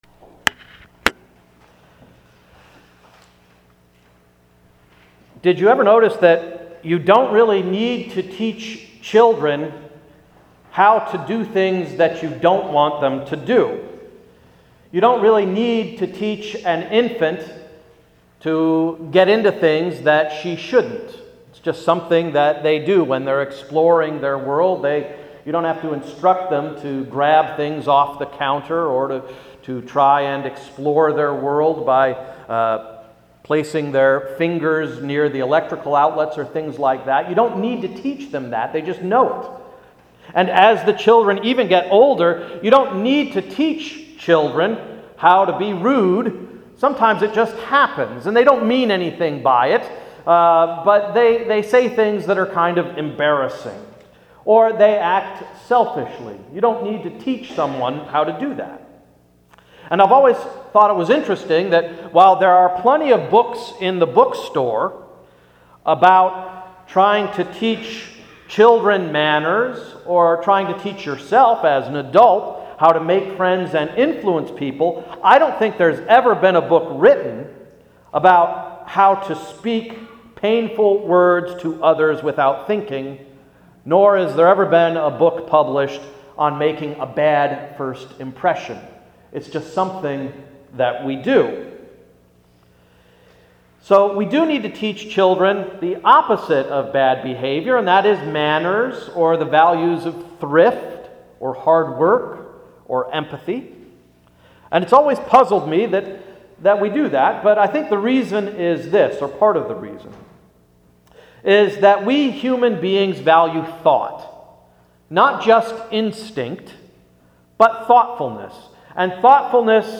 Sermon of Sunday, October 17th–“Inquire Intrepidly”